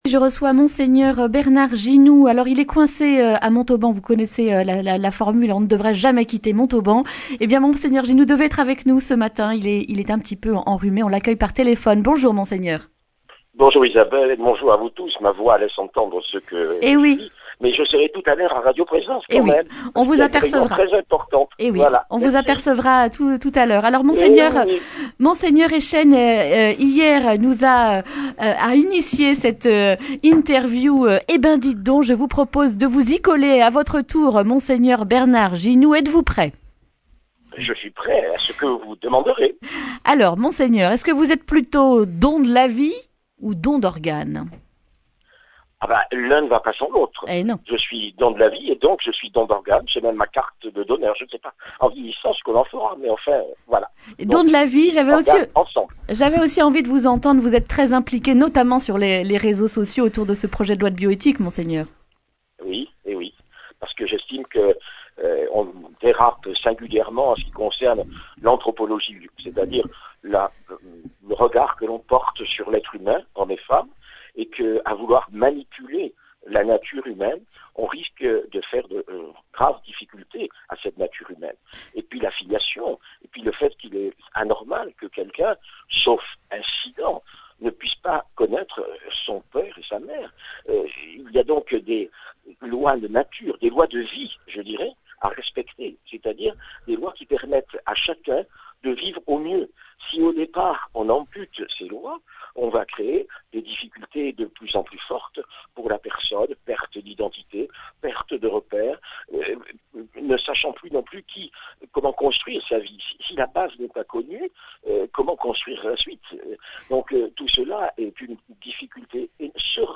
mercredi 20 novembre 2019 Le grand entretien Durée 10 min
Après Monseigneur Jean-Marc Eychenne, Monseigneur Bernard Ginoux, évêque de Montauban, se prête avec malice à notre interview spécial radio don !